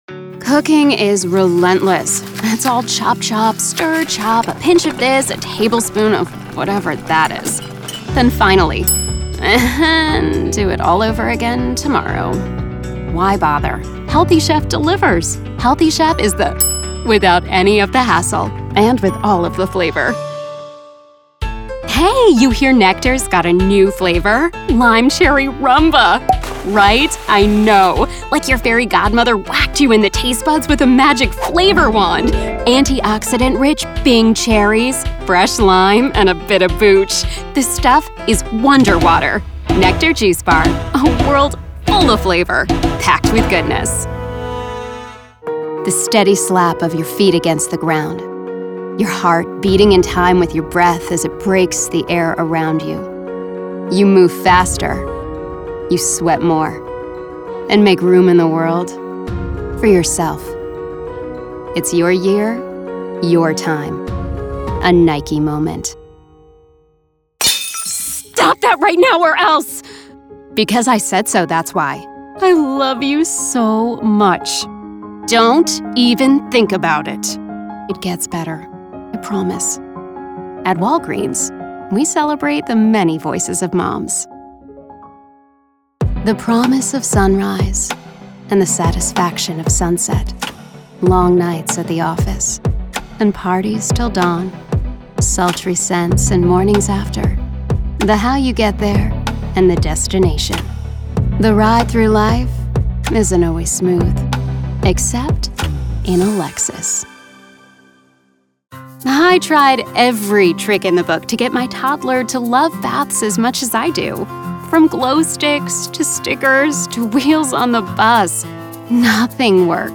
Adult, Young Adult
Has Own Studio
standard us
commercial